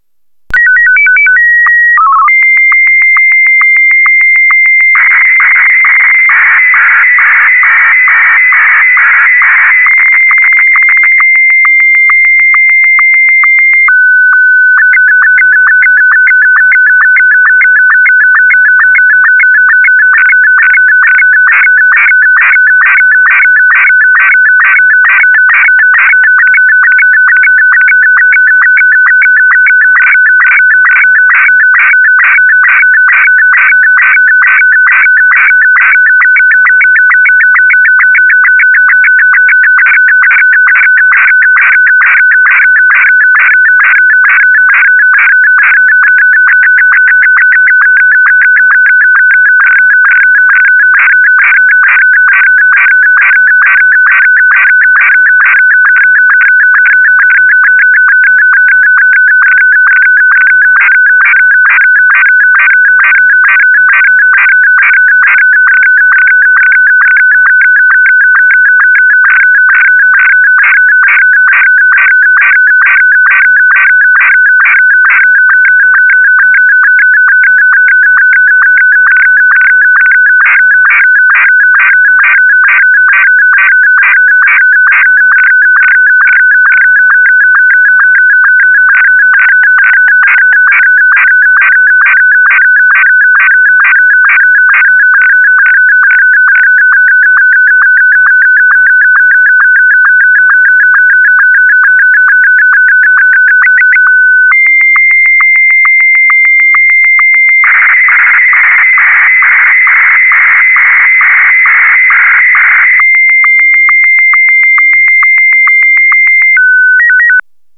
Ha megvagyunk, akkor a TX fülön belül a TX gombra kattintunk, és a program máris elkezdi fordítani a képünket hanggá.
Én a Goldwave programot használtam, majd Ogg formátumban, 44.1kHz/256kbit/s minőségben mentettem.
Saját SSTV képemet, az
SSTV.ogg